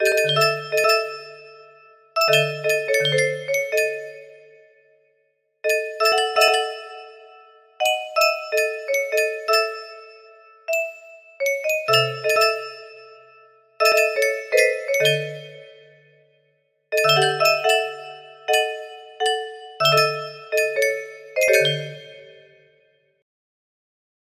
1 music box melody